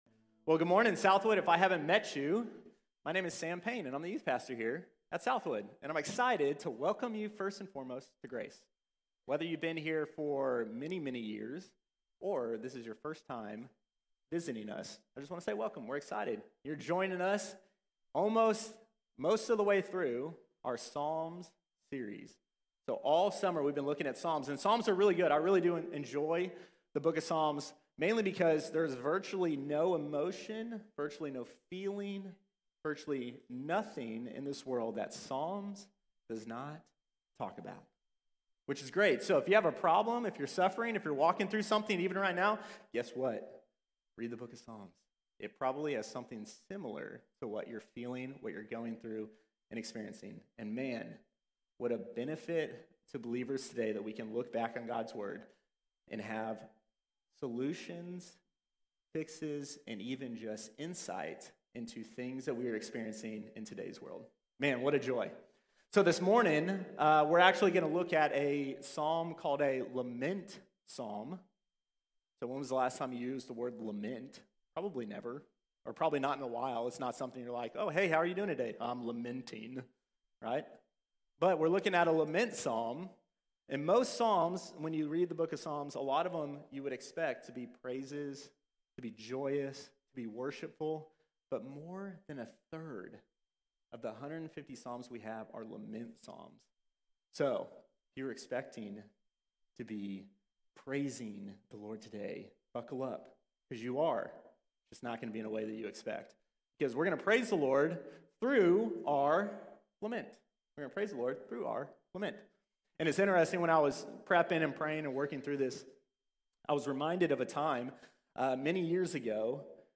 Learning to Lament | Sermon | Grace Bible Church